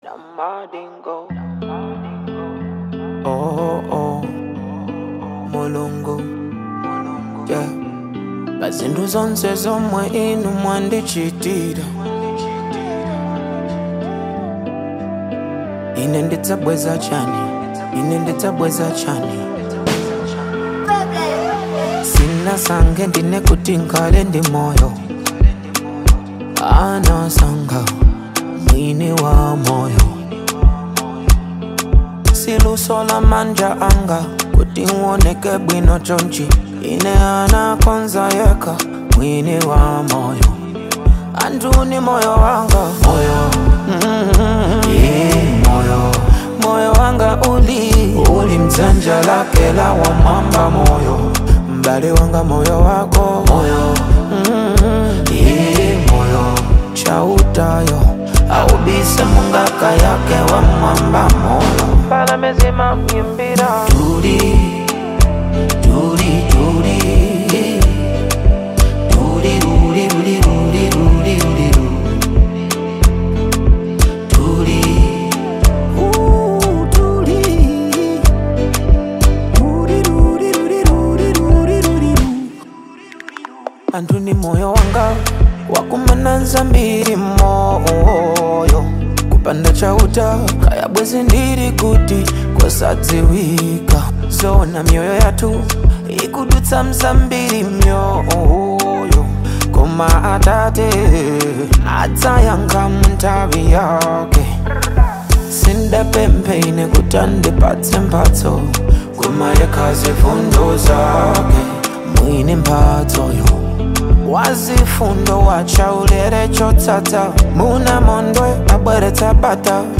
Afro-Pop